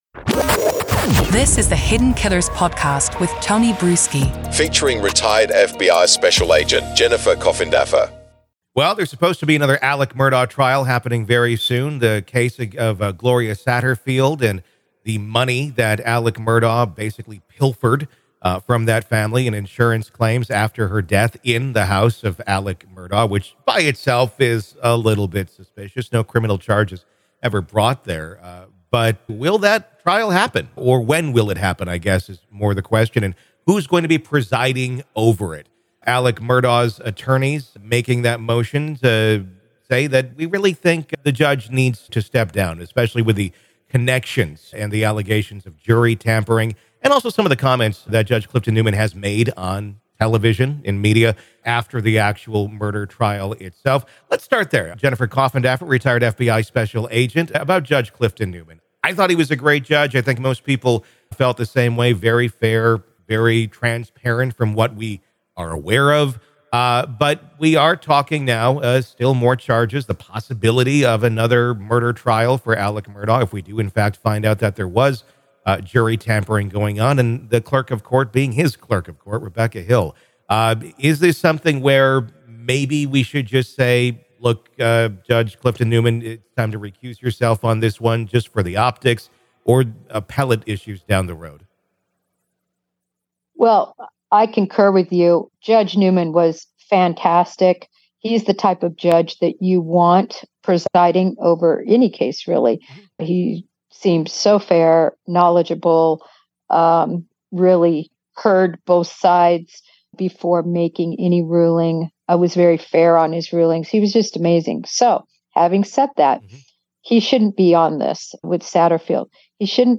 The focus of the discussion was on the allegations against Colleton County Clerk of Court Rebecca Hill, who has been accused of having improper, possibly influential contact with jurors during Alex Murdaugh's murder trial.